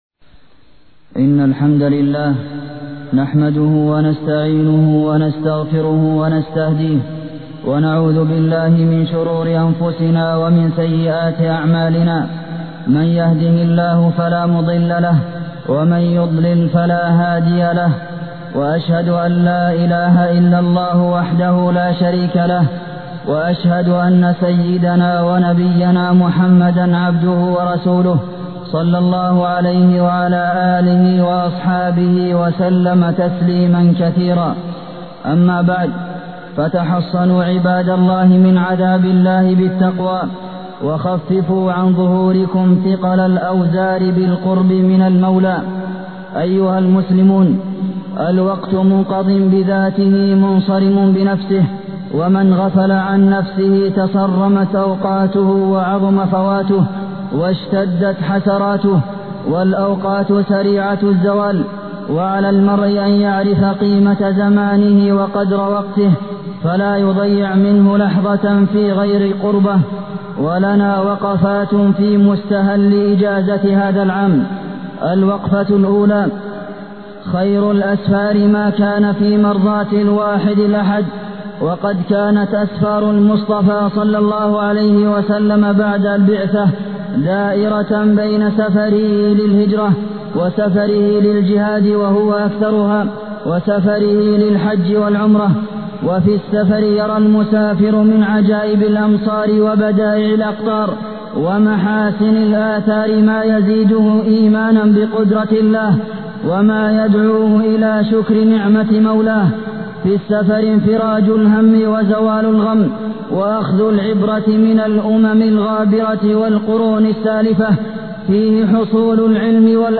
تاريخ النشر ٢٠ صفر ١٤٢٠ هـ المكان: المسجد النبوي الشيخ: فضيلة الشيخ د. عبدالمحسن بن محمد القاسم فضيلة الشيخ د. عبدالمحسن بن محمد القاسم الأجازة